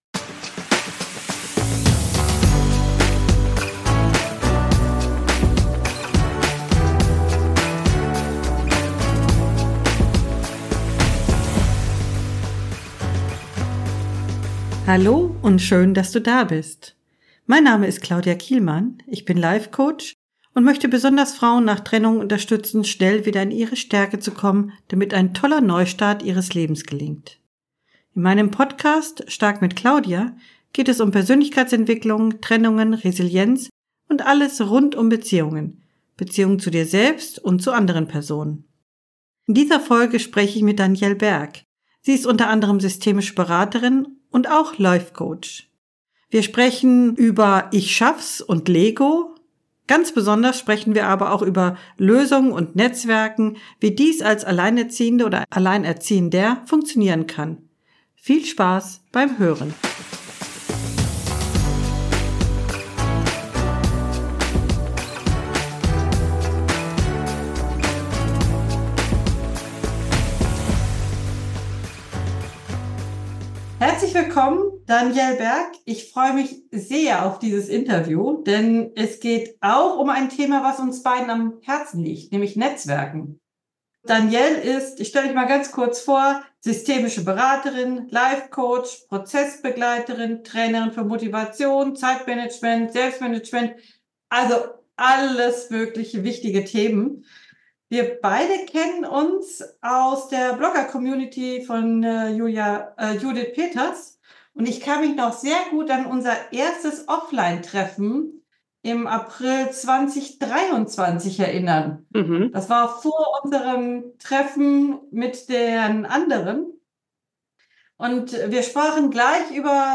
#035 Netzwerken – Wie geht das als Alleinerziehende/r - Interview